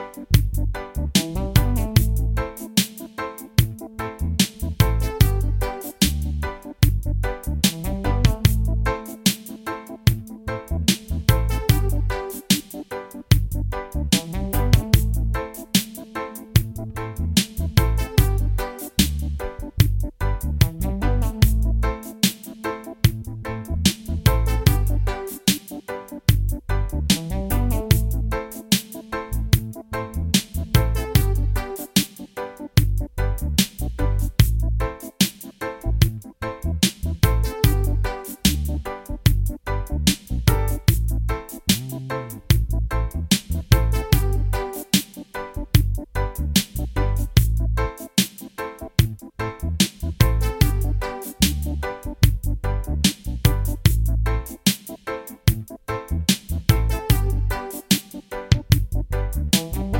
Minus Main Guitar For Guitarists 4:14 Buy £1.50